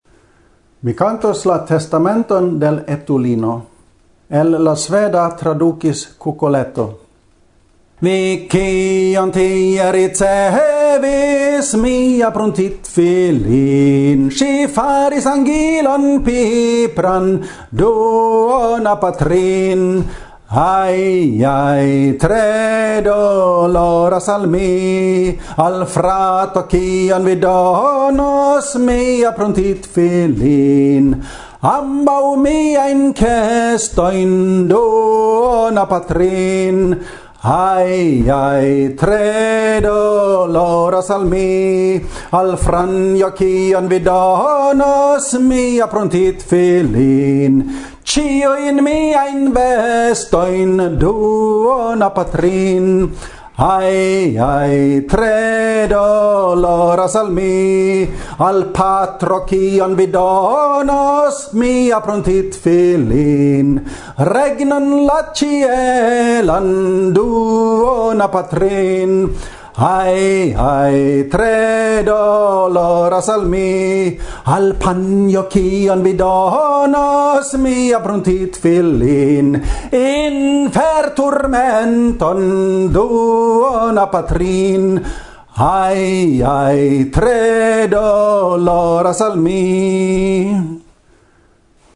Aliaj baladoj